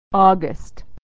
Practice - Audio Calendar - Authentic American Pronunciation